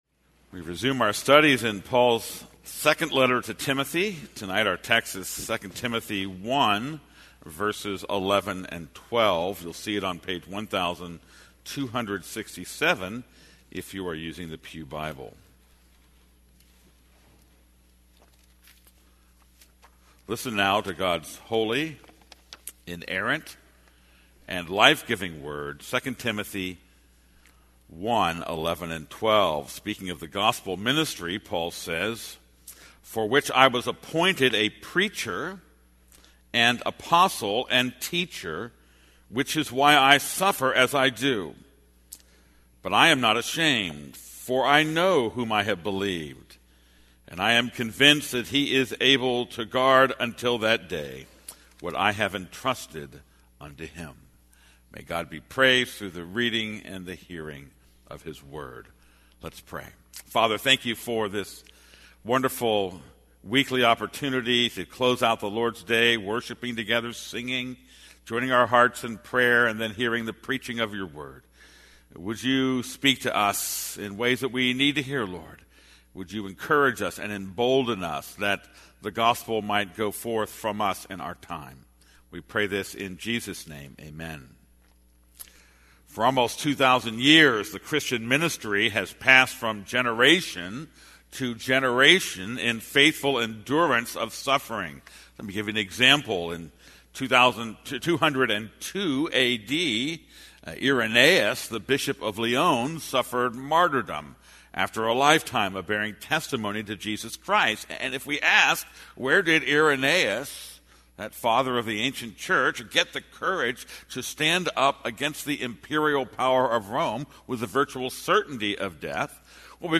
This is a sermon on 2 Timothy 1:11-12.